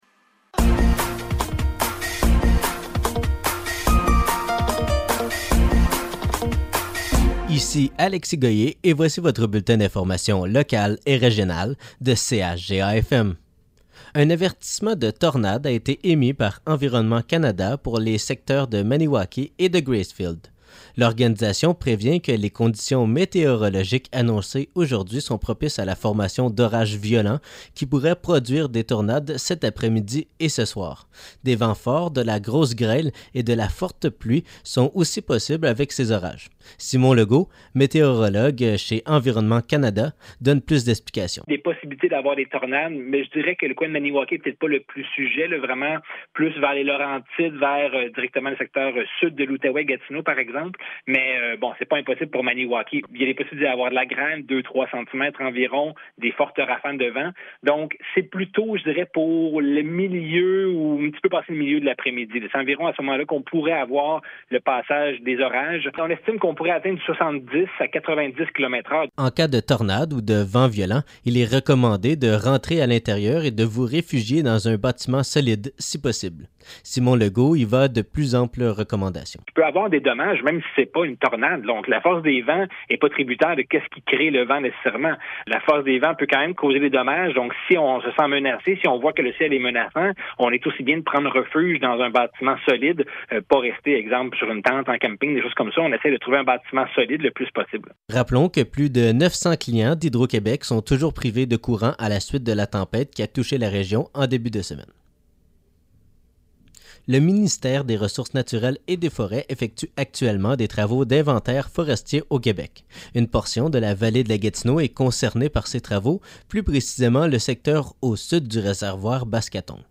Nouvelles locales - 13 juillet 2023 - 12 h